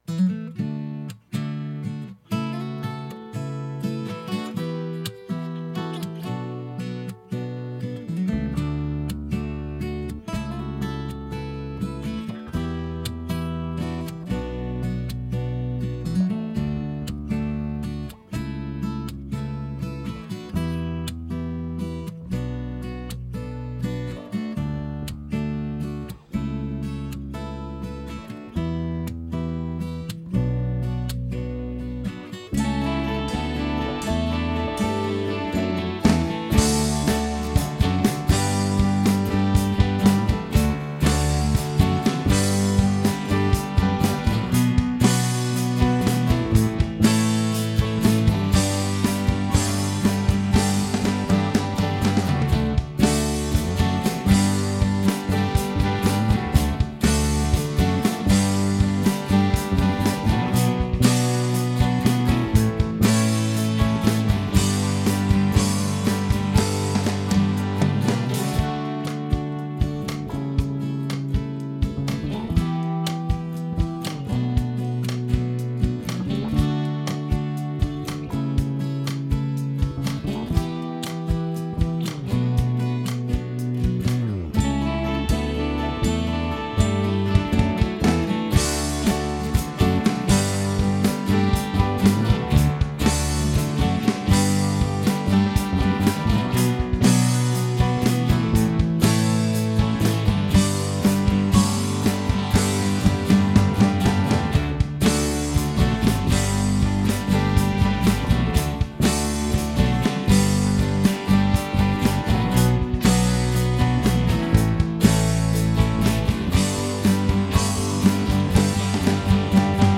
Key of D - Track Only - No Vocal